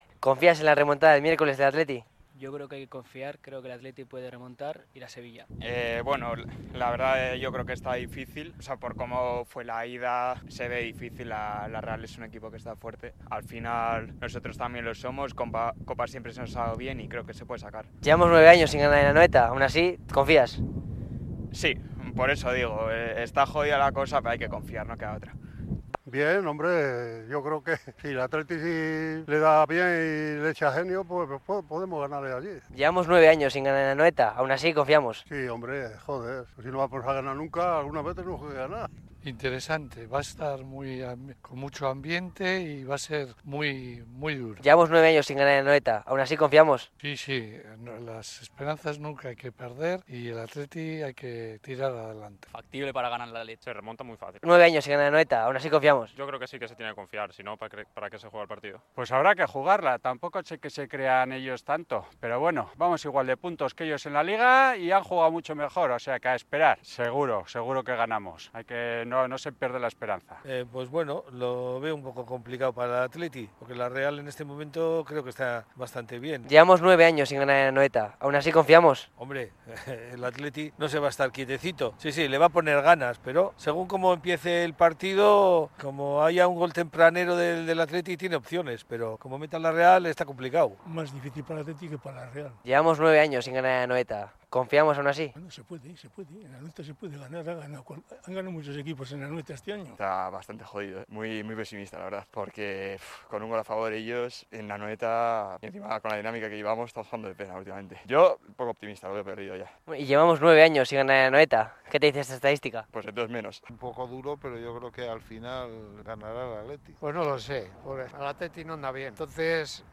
Salimos a la calle para ver cuanta confianza tienen los aficionados para la remontada en el derbi